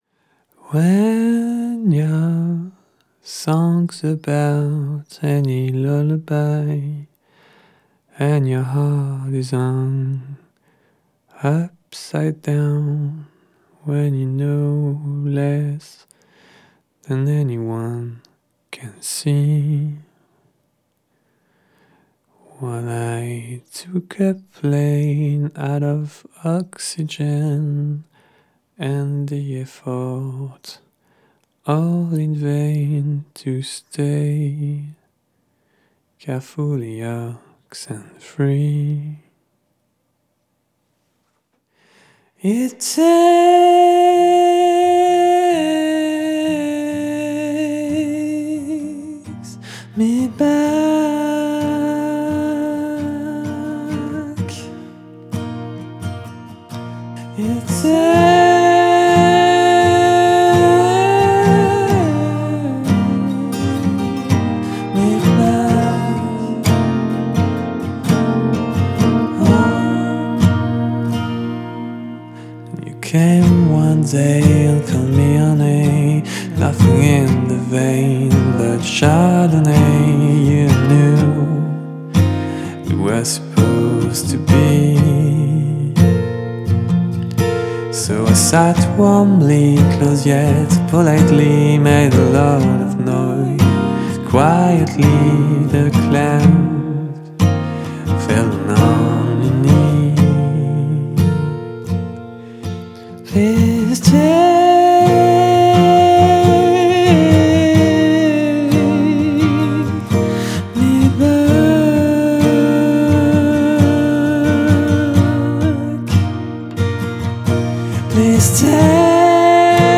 Un beau cadeau teinté de mélancolie folk